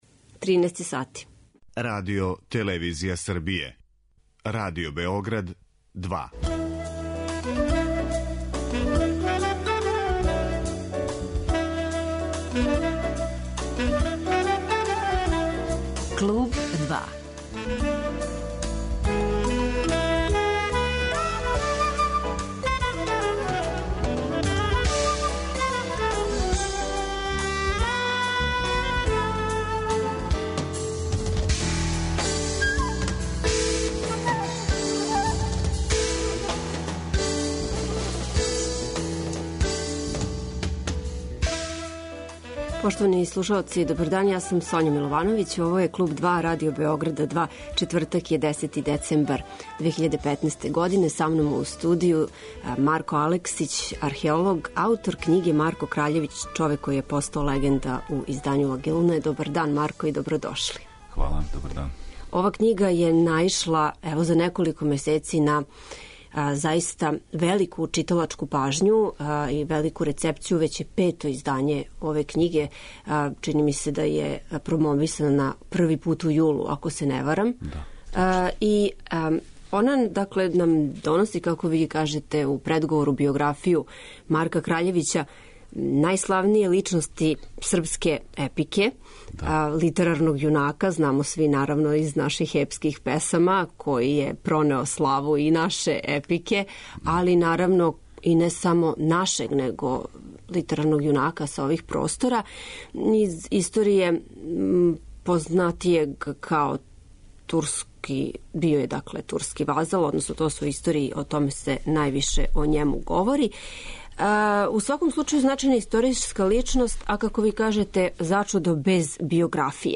Гост 'Клуба 2' биће